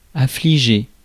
Ääntäminen
Synonyymit désolé gueux navré Ääntäminen France: IPA: /a.fli.ʒe/ Haettu sana löytyi näillä lähdekielillä: ranska Käännös Adjektiivit 1. woeful Suku: m .